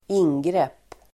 Ladda ner uttalet
Uttal: [²'in:grep:]